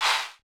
Southside Vox (17).wav